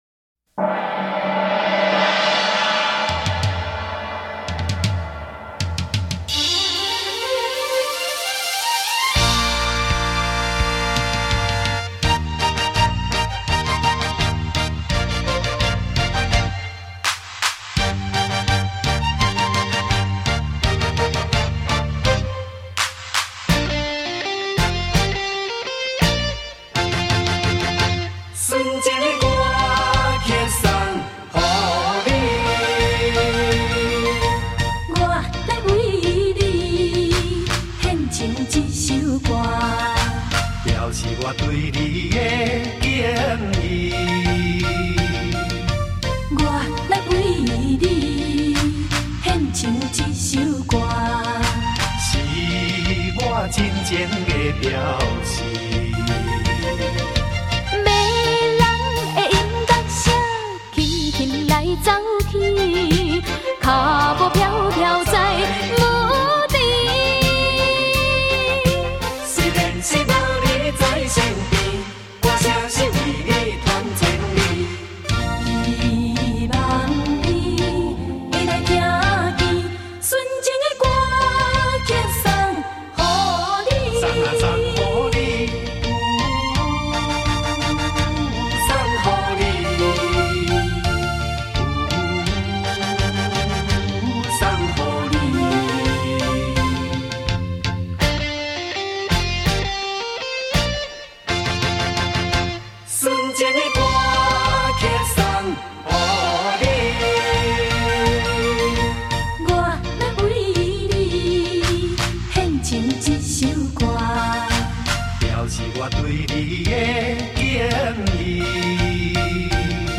怀旧的歌声